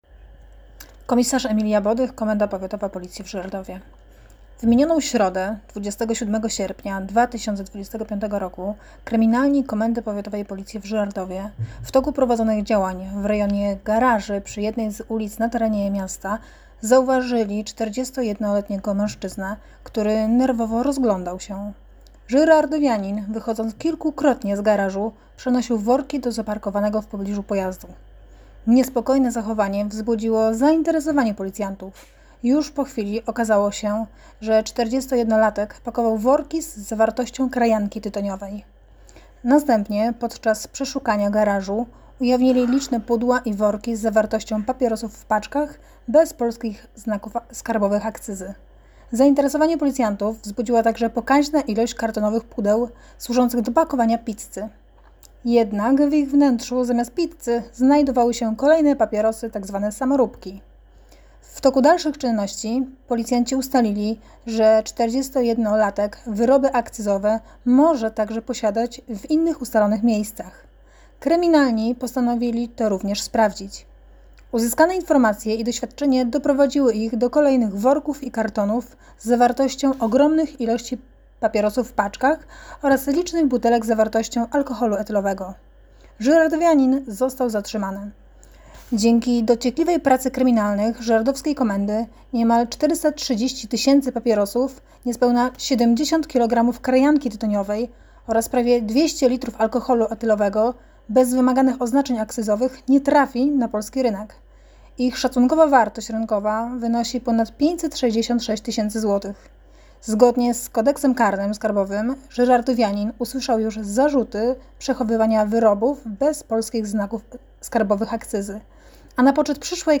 wypowiedź